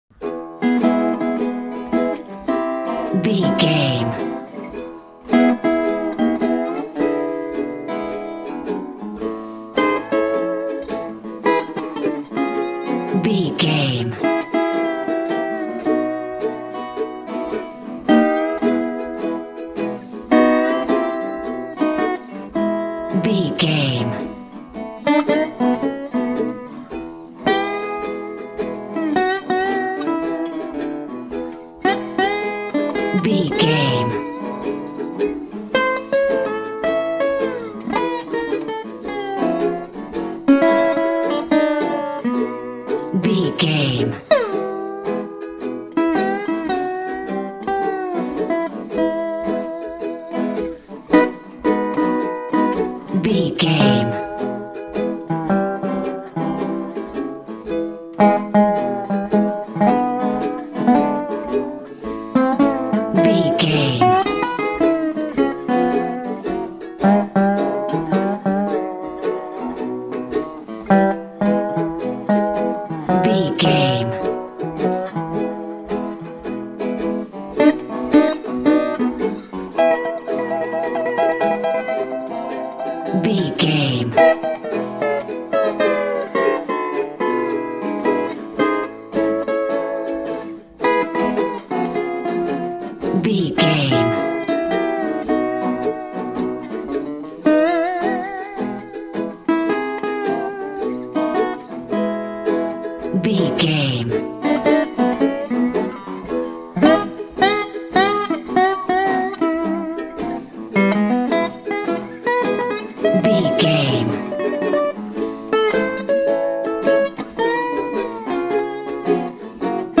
Ionian/Major
acoustic guitar
electric guitar
ukulele
dobro
slack key guitar